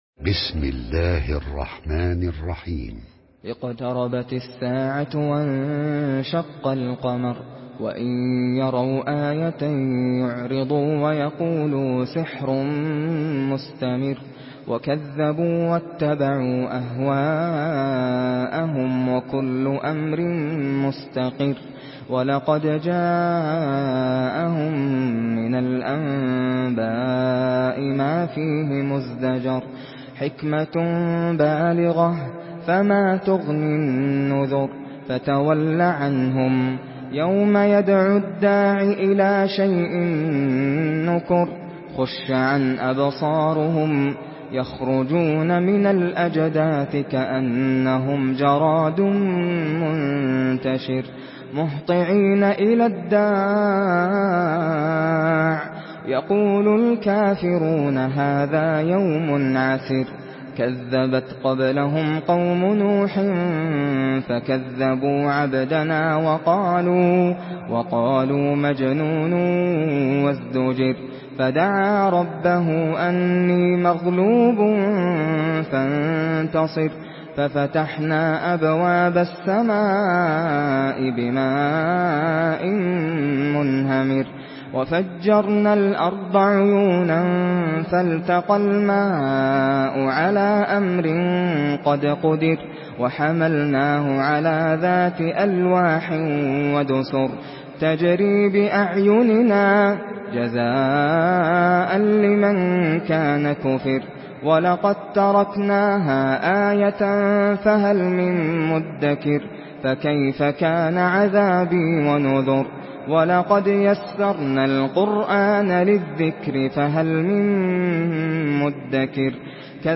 Surah Al-Qamar MP3 in the Voice of Nasser Al Qatami in Hafs Narration
Surah Al-Qamar MP3 by Nasser Al Qatami in Hafs An Asim narration.
Murattal Hafs An Asim